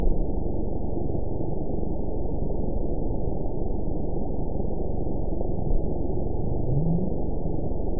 event 919891 date 01/28/24 time 00:13:46 GMT (1 year, 3 months ago) score 9.65 location TSS-AB01 detected by nrw target species NRW annotations +NRW Spectrogram: Frequency (kHz) vs. Time (s) audio not available .wav